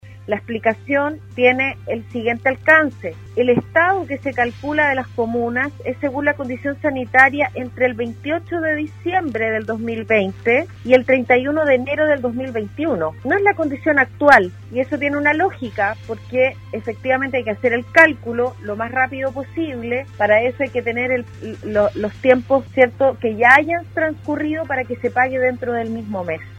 En conversación con Radio Sago, la Seremi de Desarrollo Social de la región de Los Lagos, Soraya Said, entregó detalles del nuevo Ingreso Familiar de Emergencia IFE y Bono Covid anunciado por el Presidente Piñera en su reciente visita a la Región de Los Lagos.